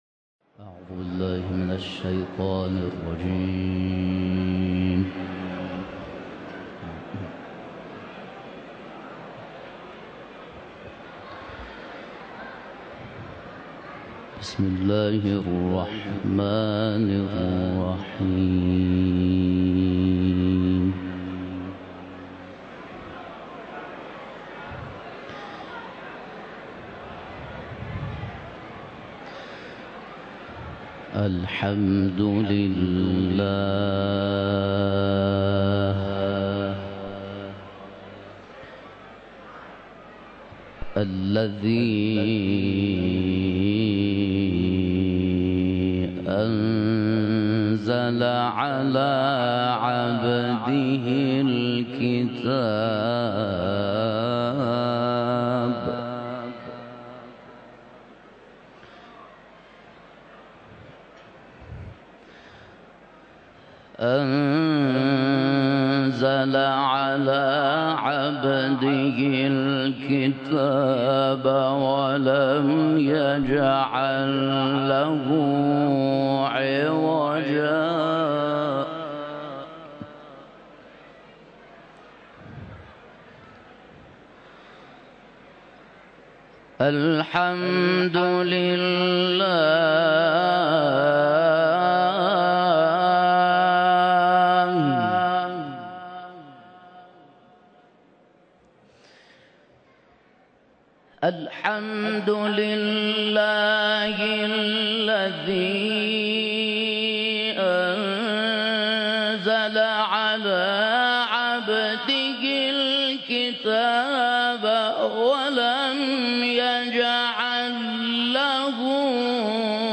تلاوت
در حسینیه امام خمینی(ره)